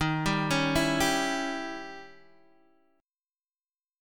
Eb7b9 chord